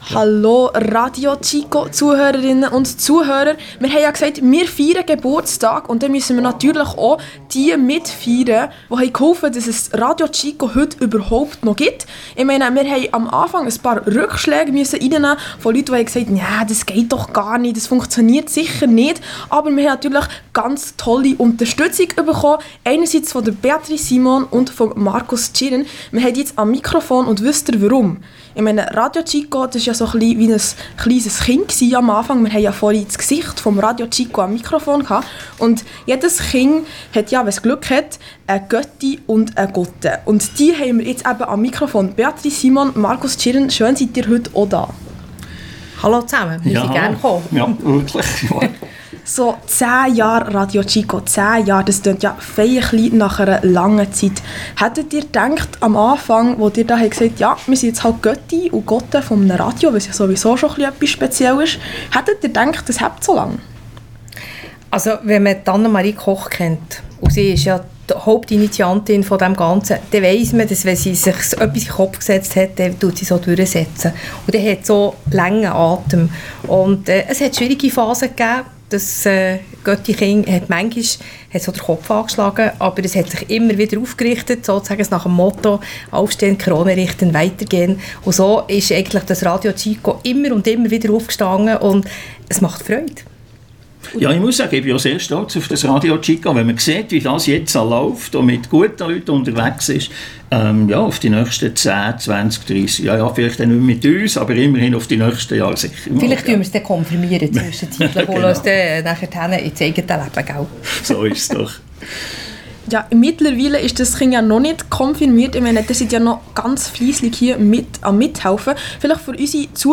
Am 8. Januar haben wir in unserem Studio den 10. Geburtstag von RadioChico Schweiz gefeiert.
Interview